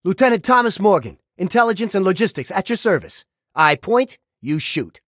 Category:Operation: Anchorage audio dialogues Du kannst diese Datei nicht überschreiben.